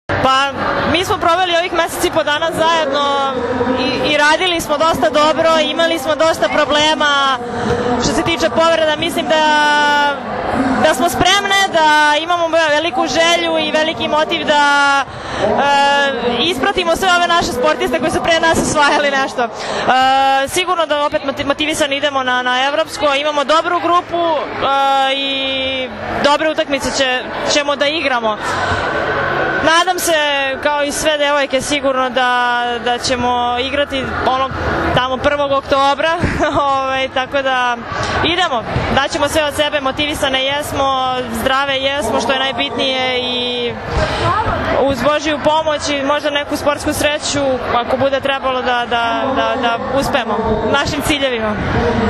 IZJAVA JELENE BLAGOJEVIĆ